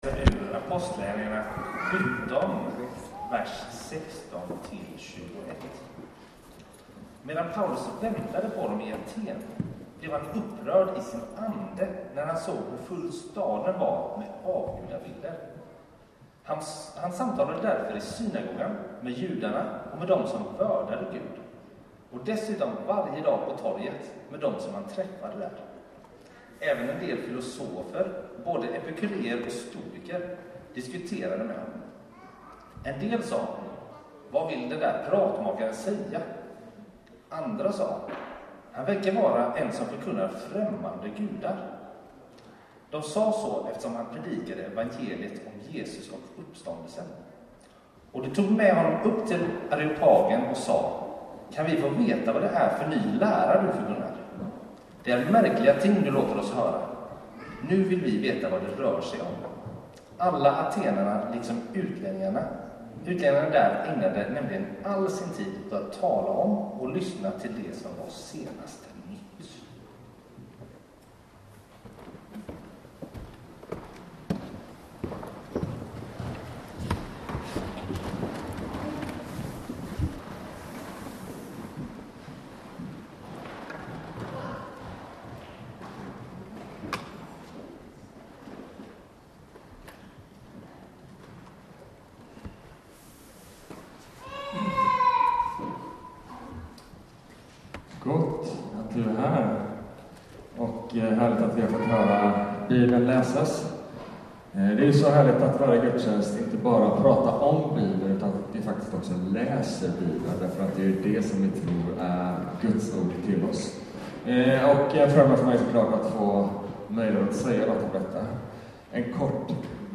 2026 Launch Sermon Player 5:e och sista delen av en kyrka för staden.